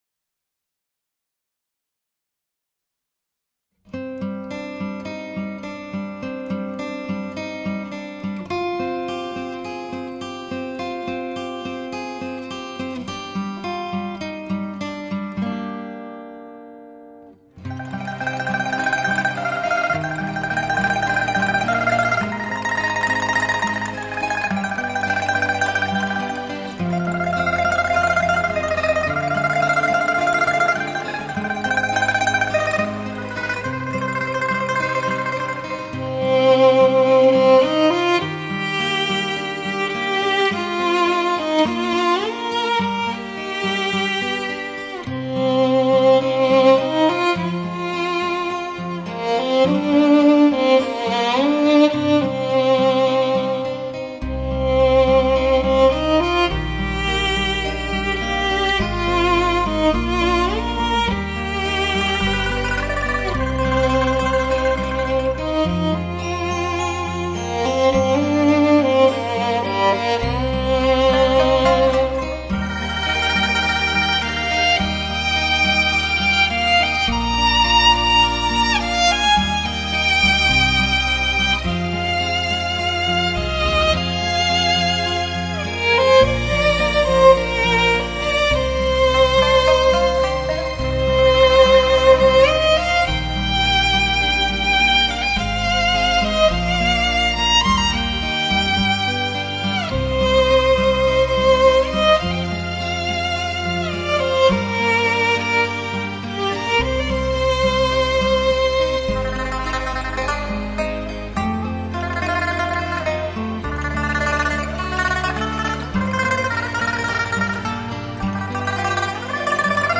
[2006-11-27]纯音欣赏--微风细雨